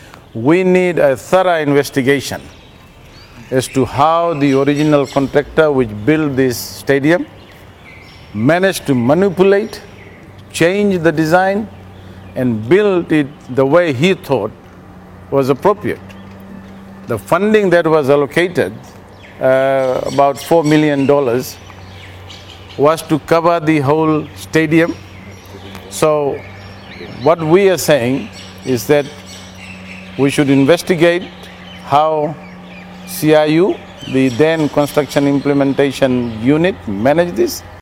Finance Minister Professor Biman Prasad during his visit to the Govind Park Stadium in Ba.